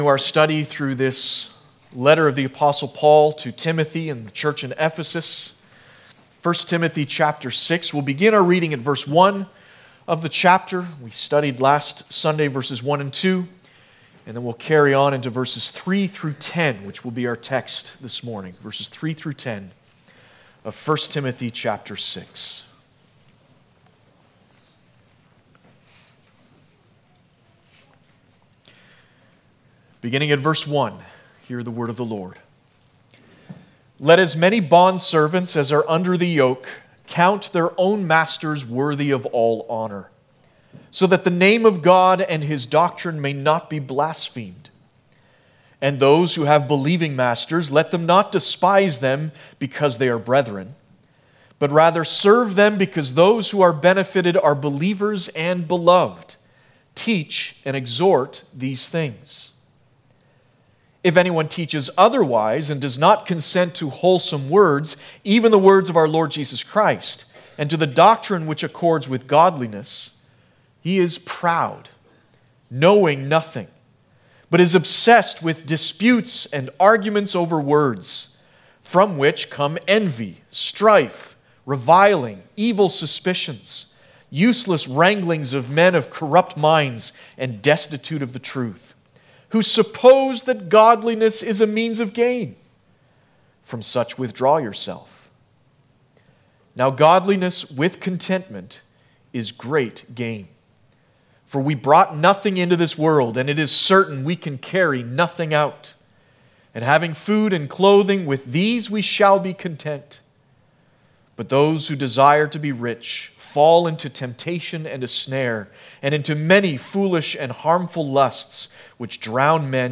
1 Timothy Passage: 1 Timothy 6:3-10 Service Type: Sunday Morning « Jesus said